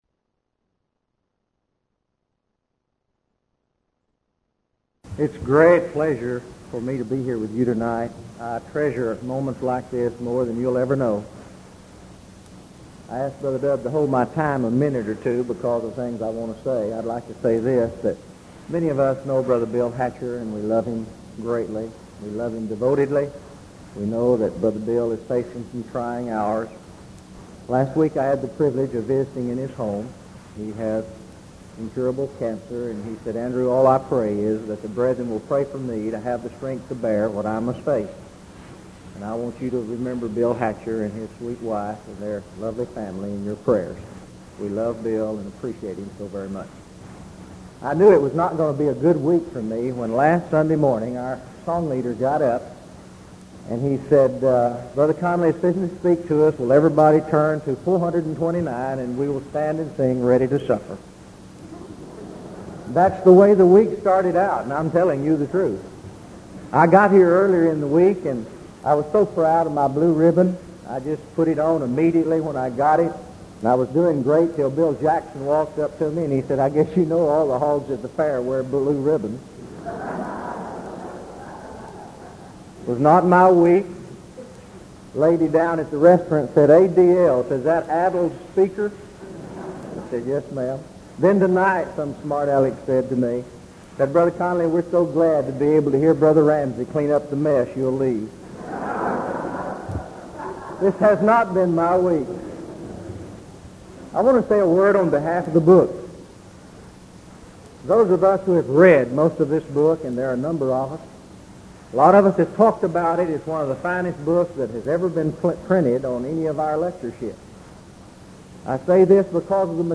Event: 1986 Denton Lectures Theme/Title: Studies in Galatians
lecture